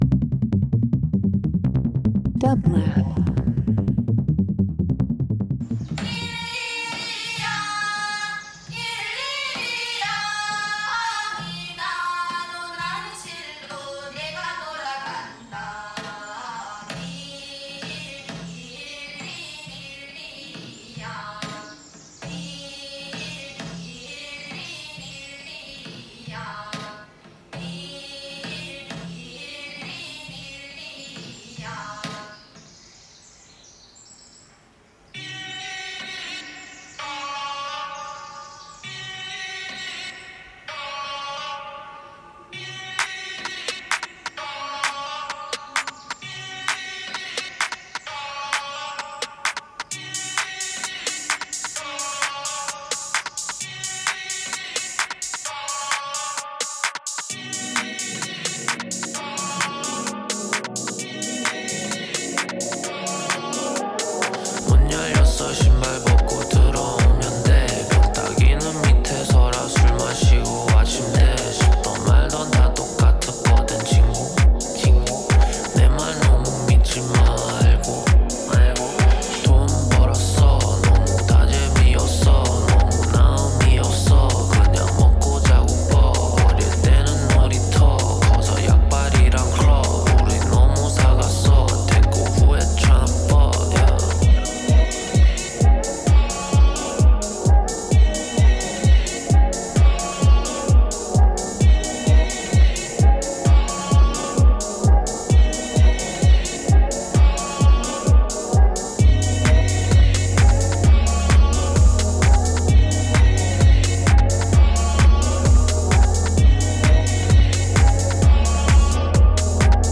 Breaks Dance Electronic House